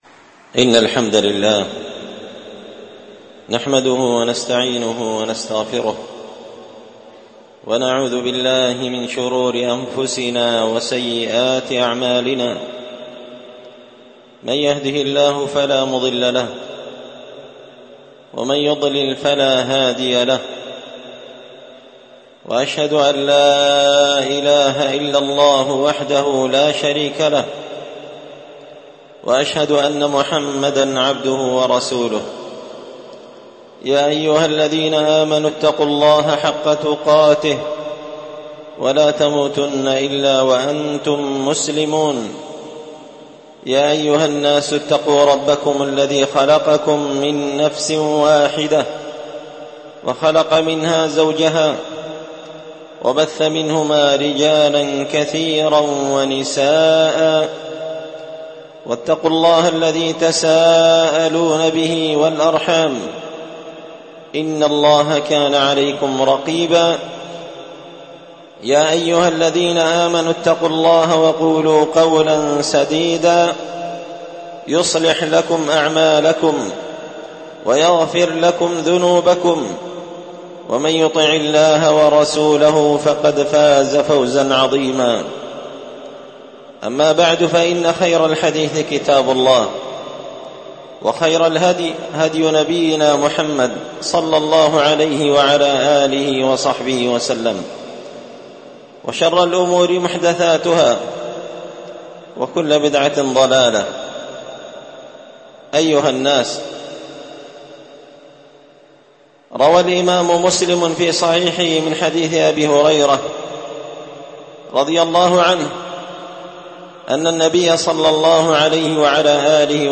ألقيت هذه الخطبة في دار الحديث السلفية بمسجد الفرقان قشن-المهرة-اليمن…
خطبة جمعة بعنوان: